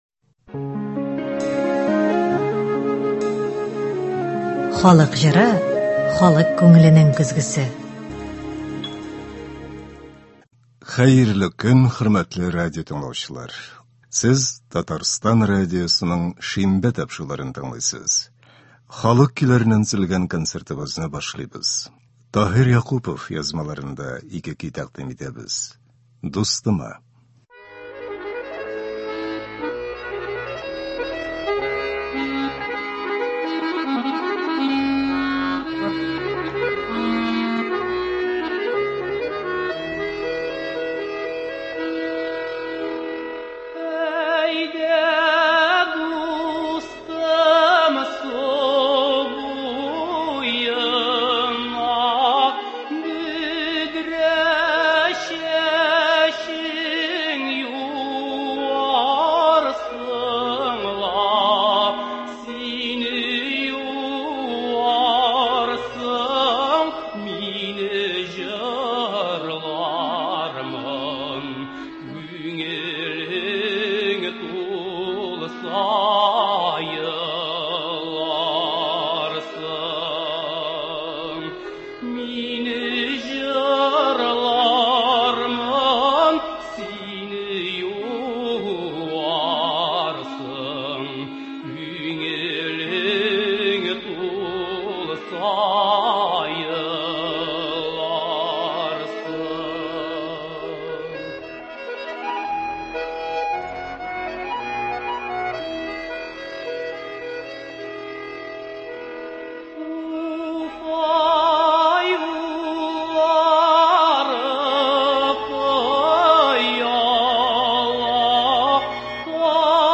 Татар халык көйләре (03.02.24)